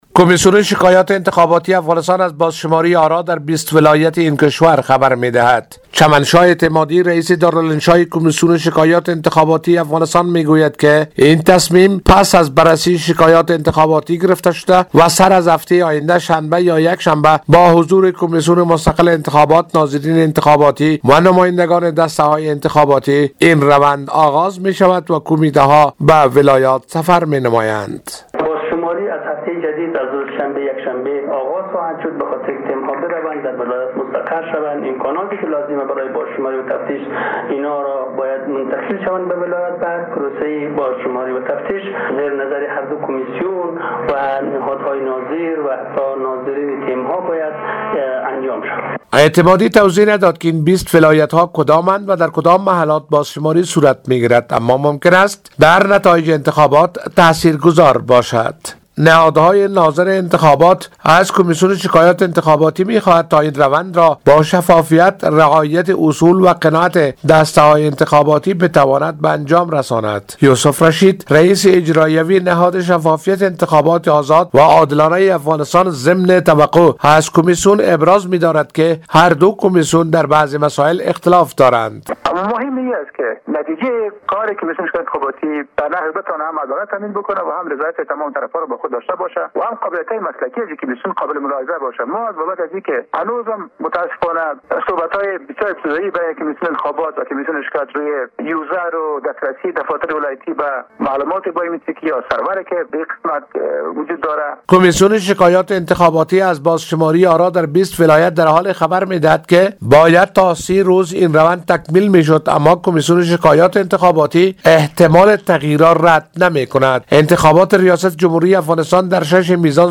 گزارش خبرنگار رادیودری: